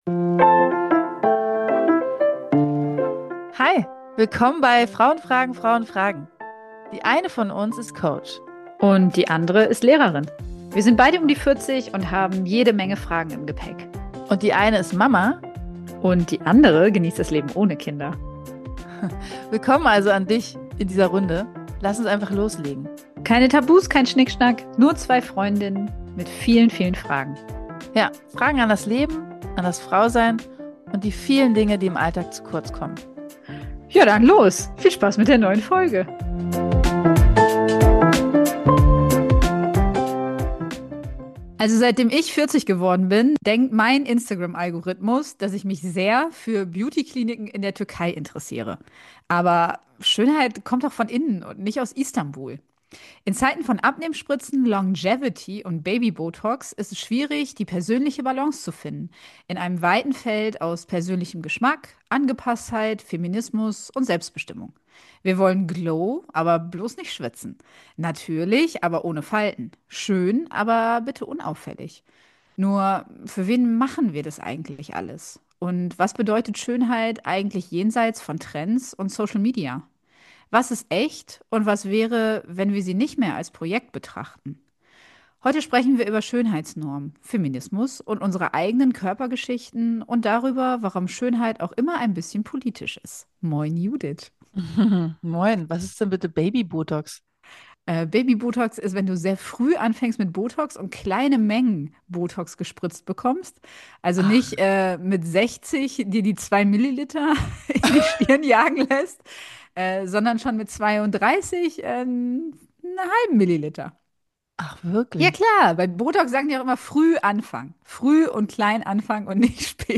Aber mit ordentlich Humor und ohne Filter.